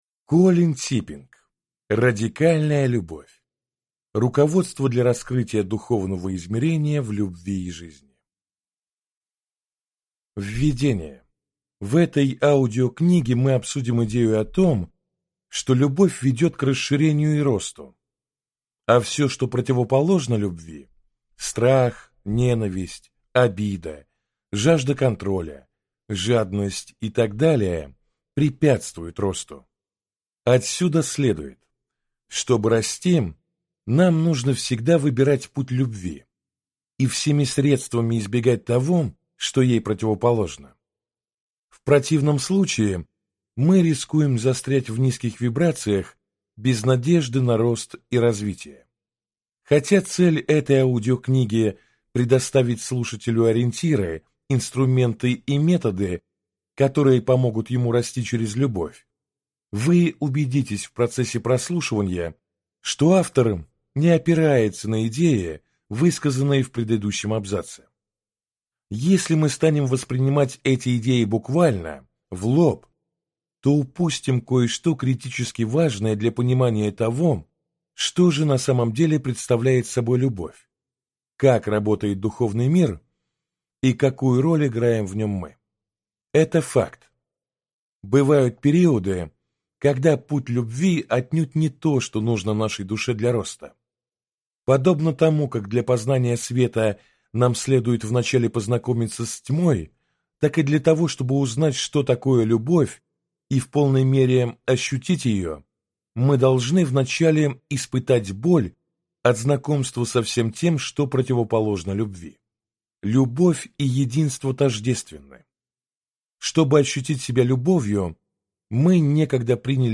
Аудиокнига Радикальная Любовь. Руководство для раскрытия духовного измерения в любви и жизни | Библиотека аудиокниг